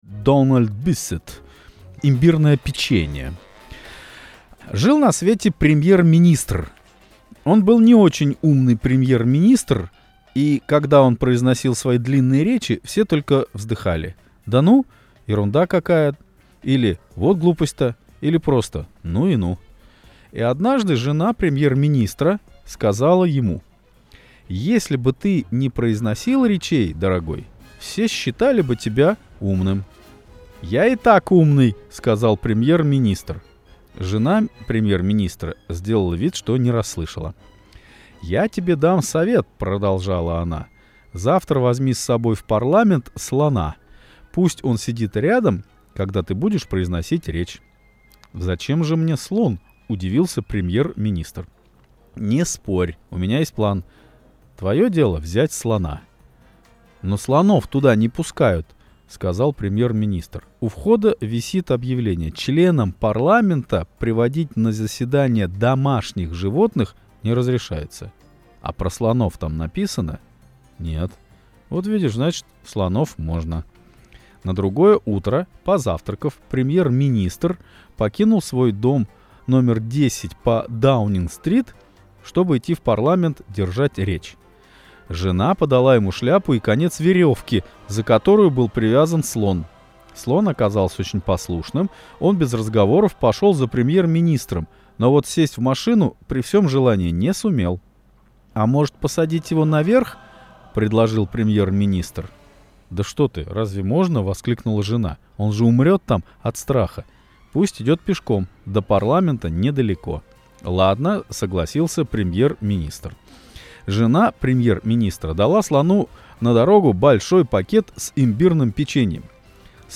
Аудиосказка «Имбирное печенье»